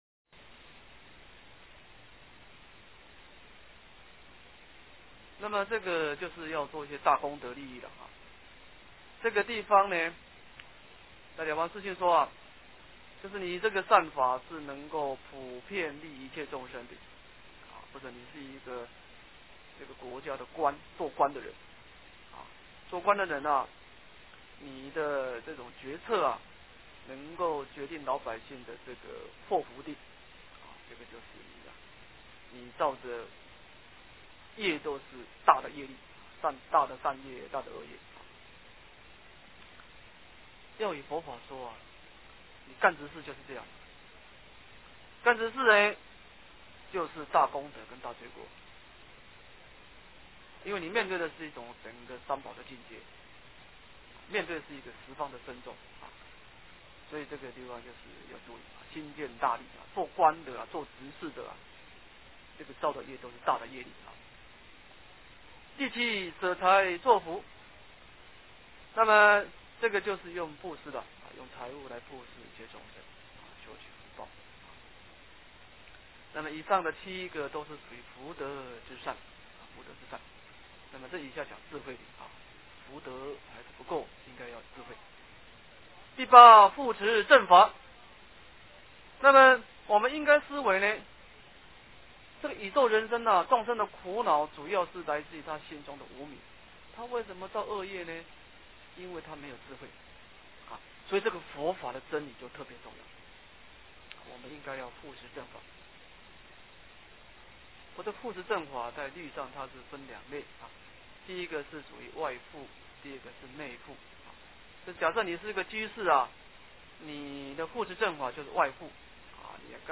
印光法师文钞20 - 诵经 - 云佛论坛